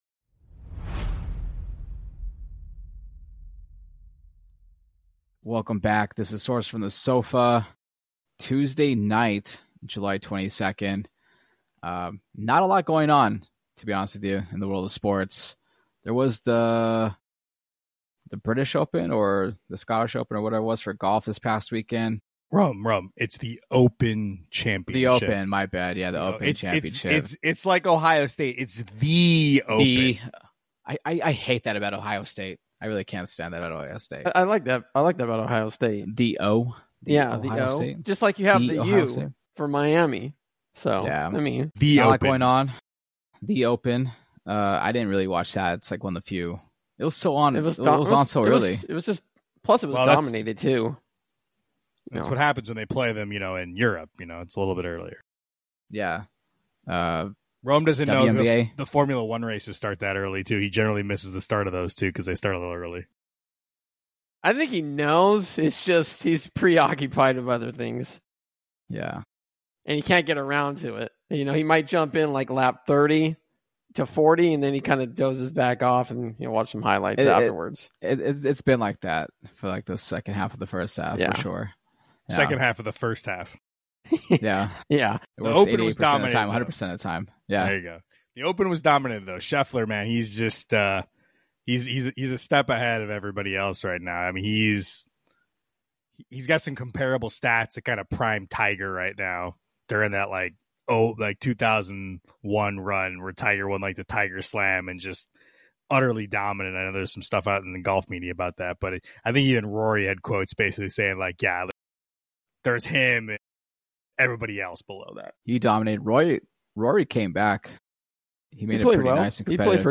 There isn't a whole lot of things happening in the world of sports, however the guys decided to pump out a pod anyways.